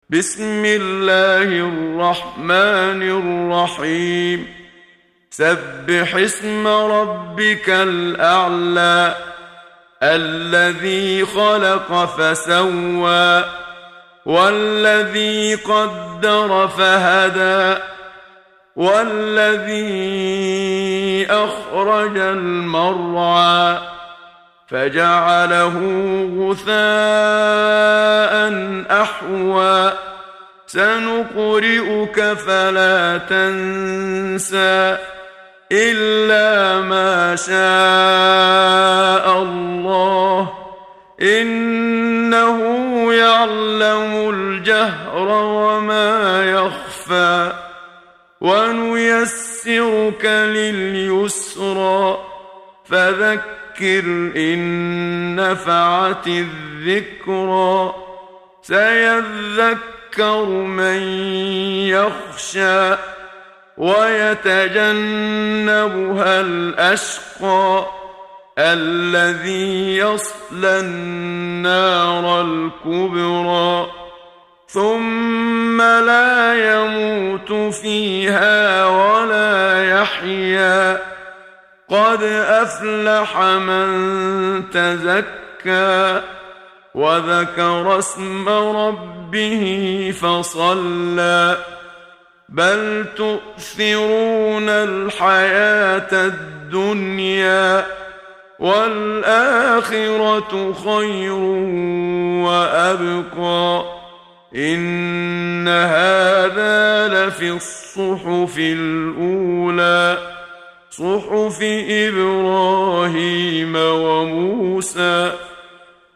محمد صديق المنشاوي – ترتيل